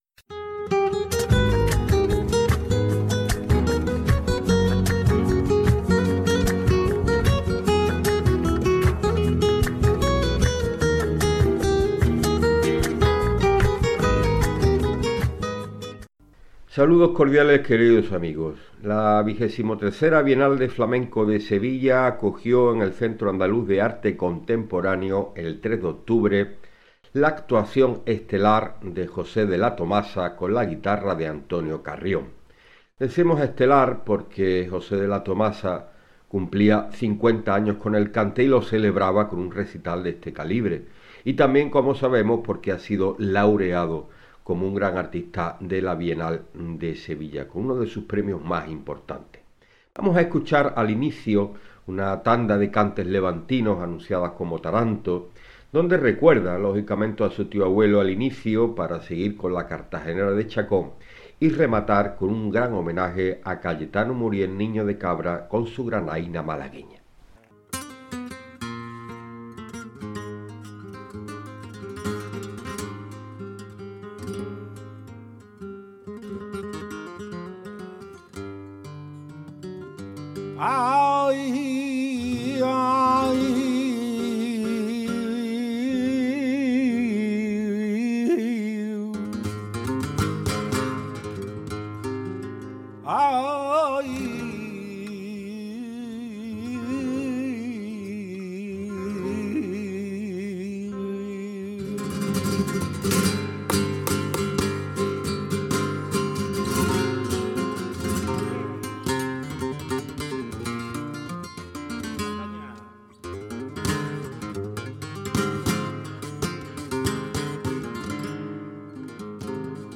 Algunos pasajes del espectáculo